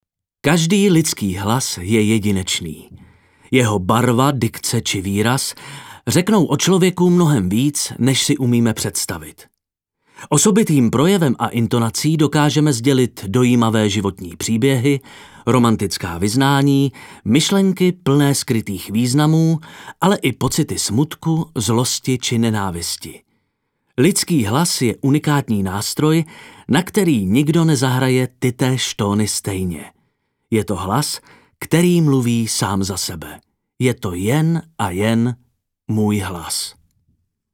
Dabing:
Ukázka hlasu: